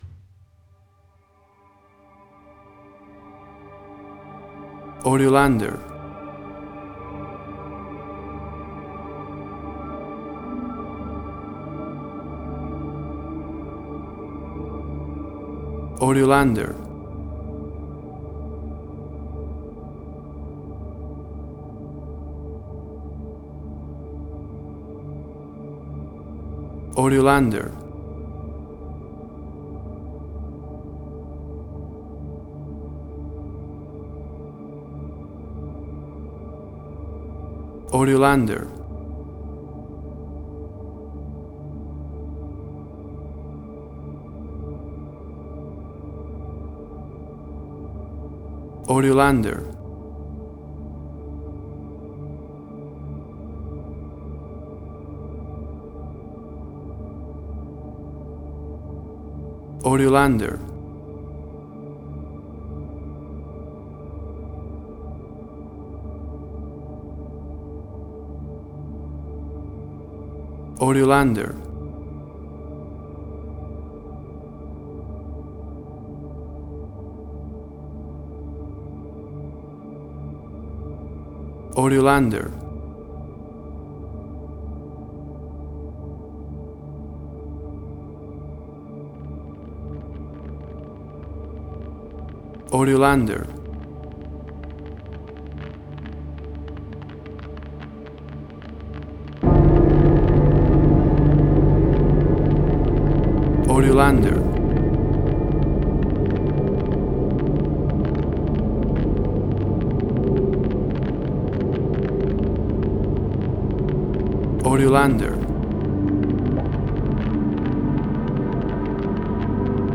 Post-Electronic.